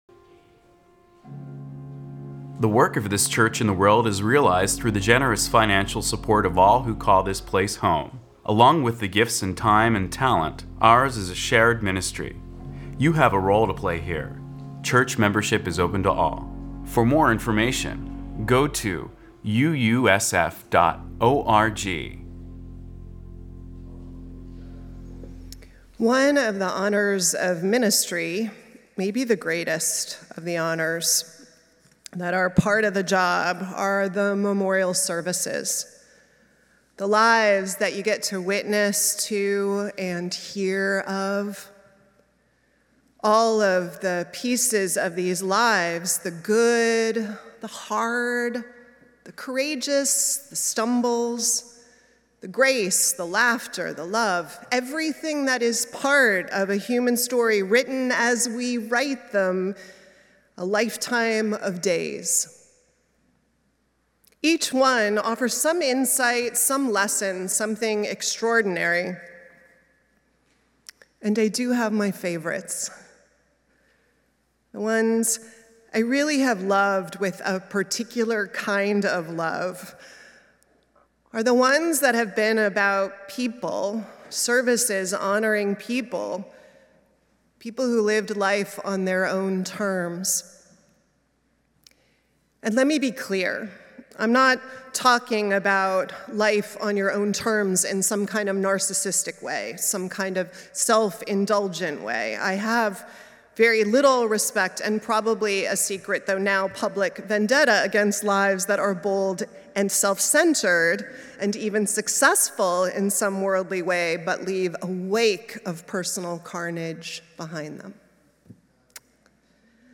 SERVICE OF PROMISE AND REPAIR - All Souls Unitarian Church